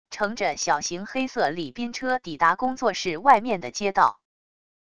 乘着小型黑色礼宾车抵达工作室外面的街道wav音频